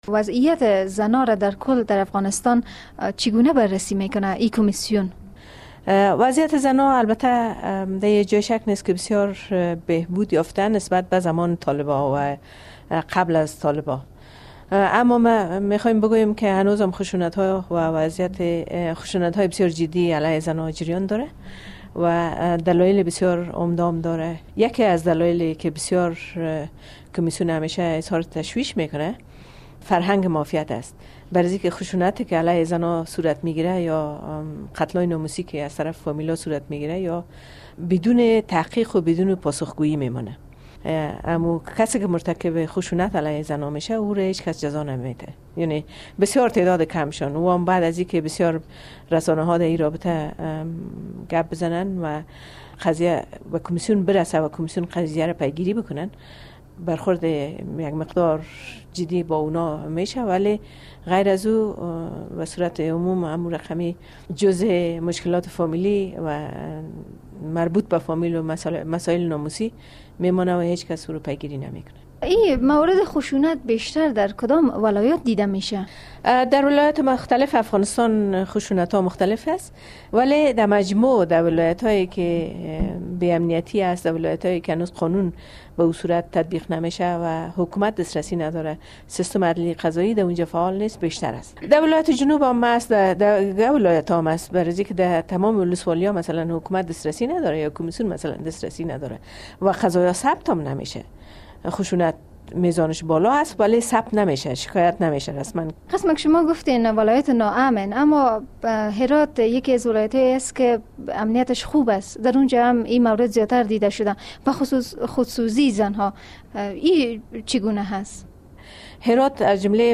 مصاحبه با سیما سمر در رابطه به خشونت علیهء زنان در افغانستان